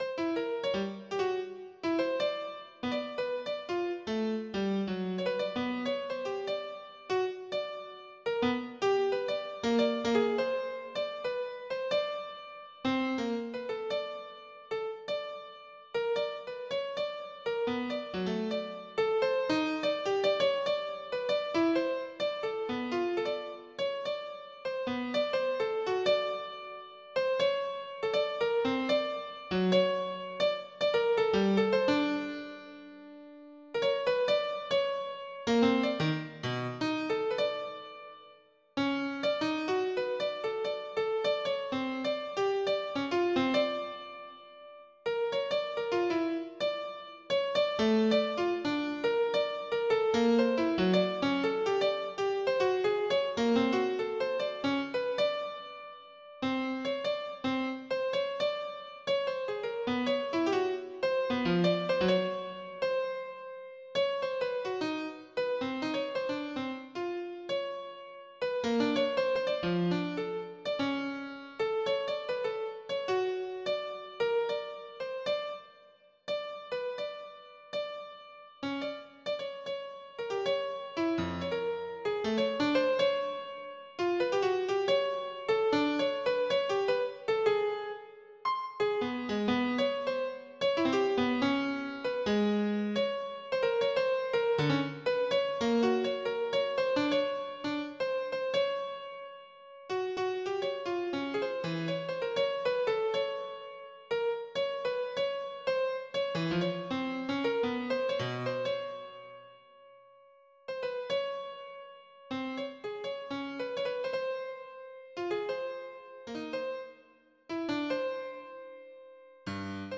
• Качество: 320, Stereo
без слов
пианино
море